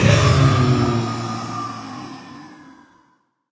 sounds / mob / blaze / death.ogg
death.ogg